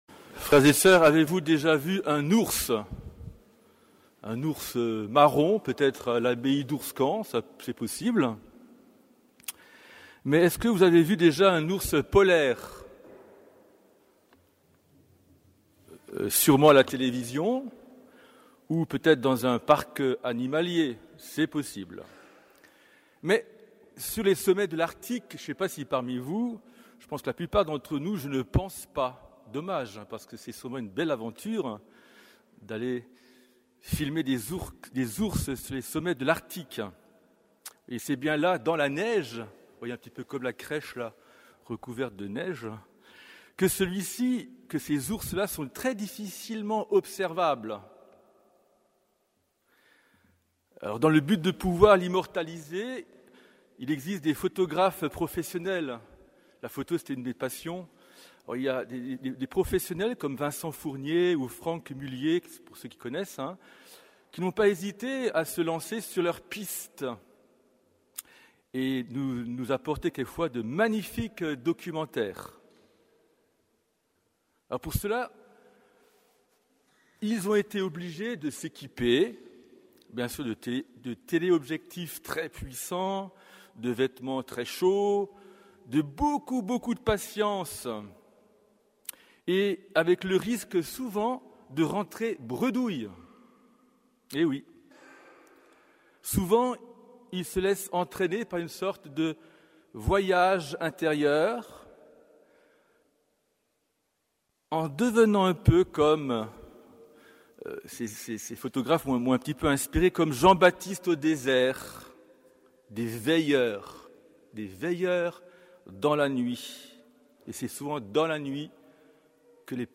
Homélie du Troisième dimanche de l'Avent